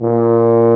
BRS TUBA F0J.wav